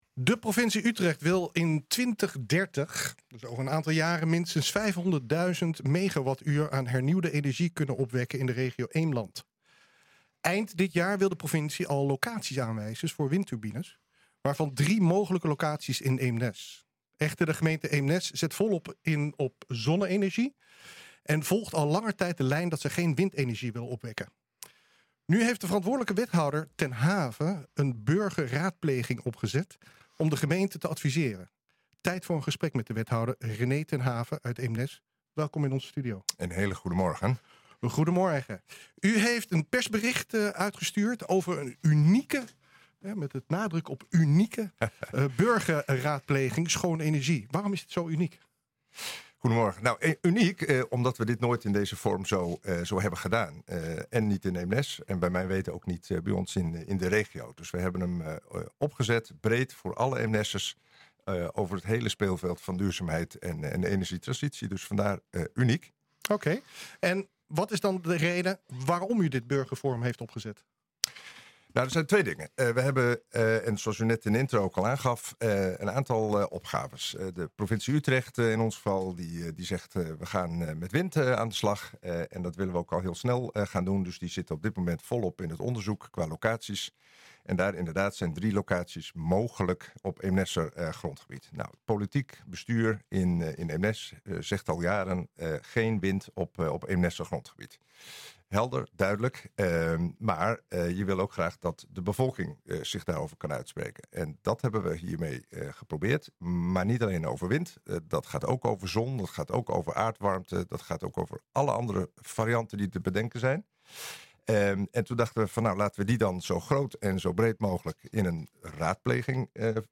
Nu heeft de verantwoordelijk wethouder ten Have een burgerraadpleging opgezet om de gemeente te adviseren. Tijd voor een gesprek met we...